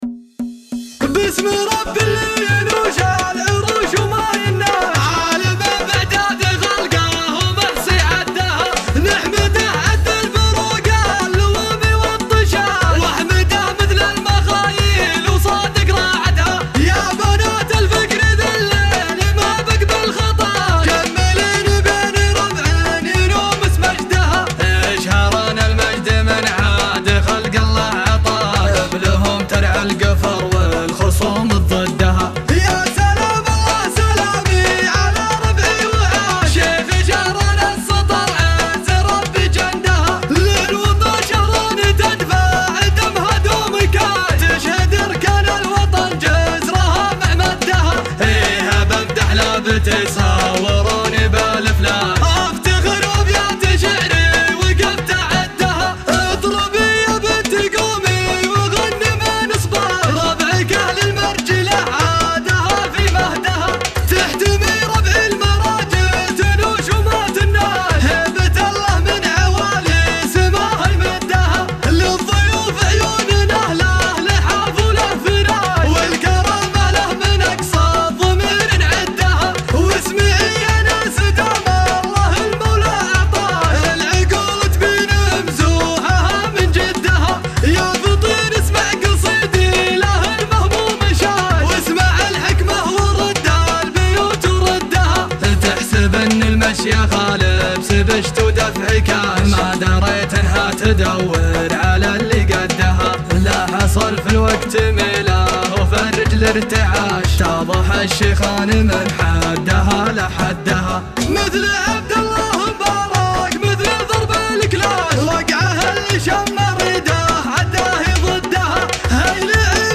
حماسي